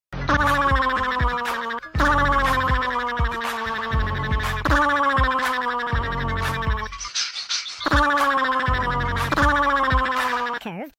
Tom-Lizard-Scream-New-Version.mp3